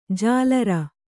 ♪ jālara